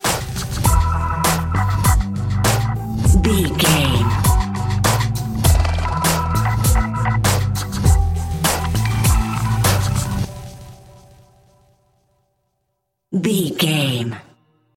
Aeolian/Minor
synthesiser
drum machine
hip hop
Funk
neo soul
acid jazz
energetic
bouncy
funky